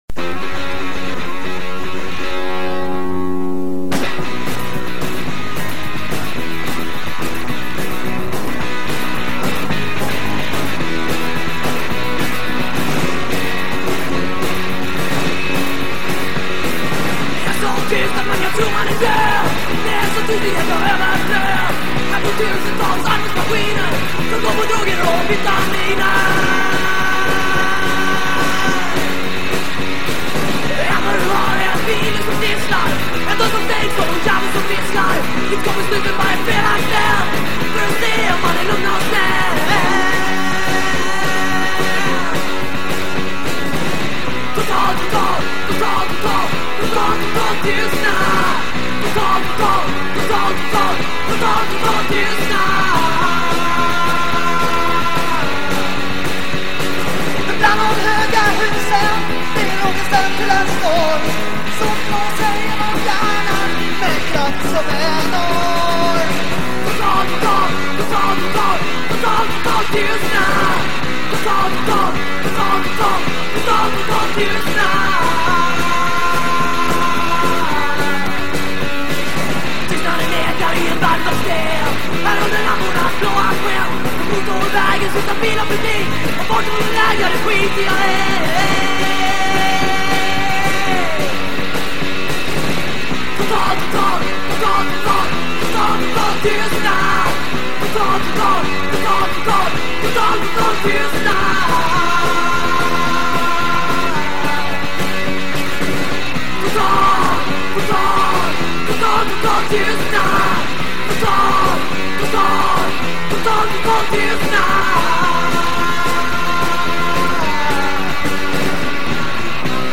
Umeås stolthet bland punk.